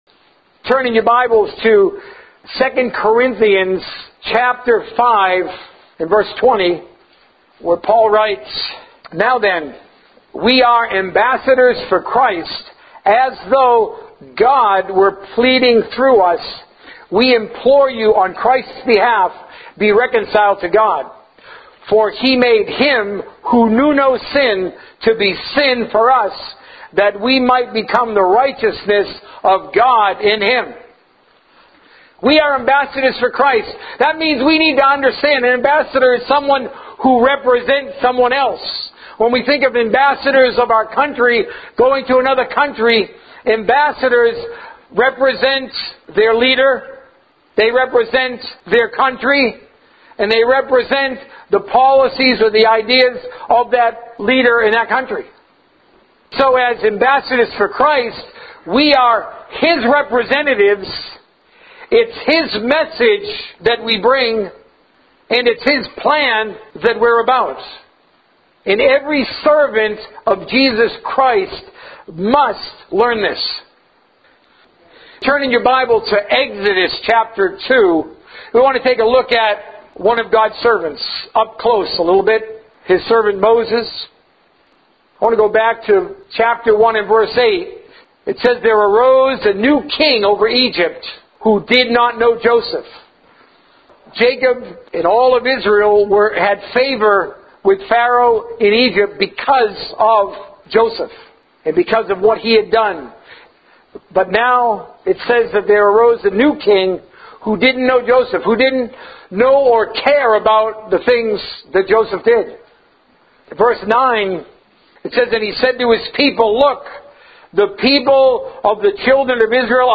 A message from the series "Serving Jesus." Bible bits from Matthew